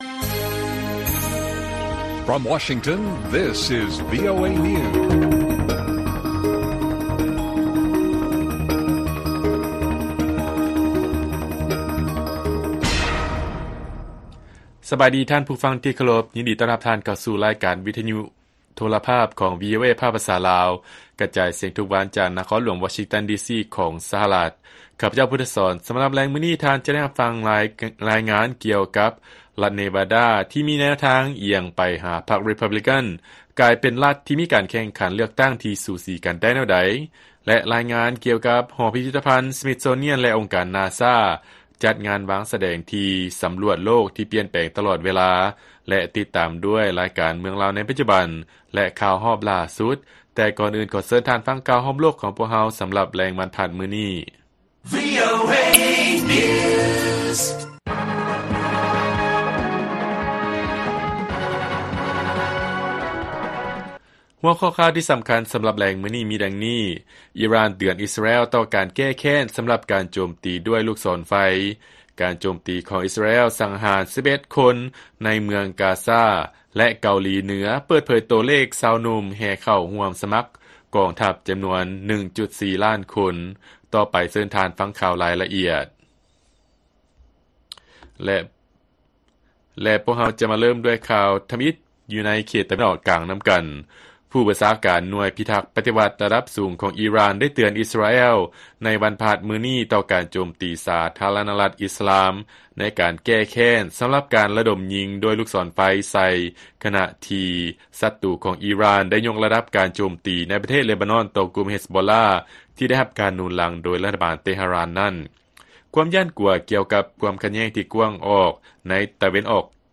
ລາຍການກະຈາຍສຽງຂອງວີໂອເອລາວ: ອີຣ່ານ ເຕືອນ ອິສຣາແອລ ຕໍ່ການແກ້ແຄ້ນ ສຳລັບການໂຈມຕີດ້ວຍລູກສອນໄຟ